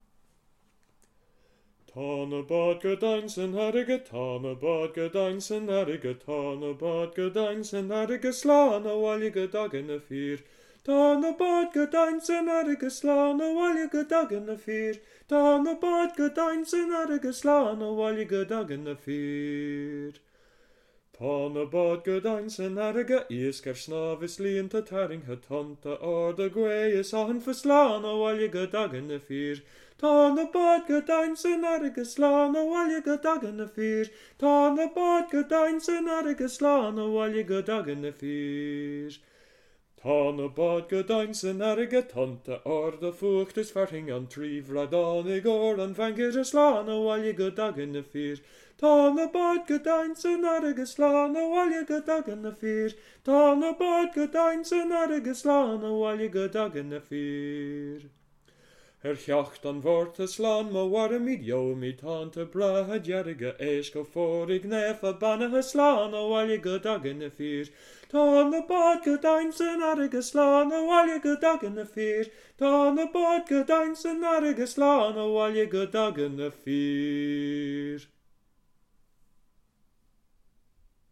Tá na Báid - pronunciation.mp3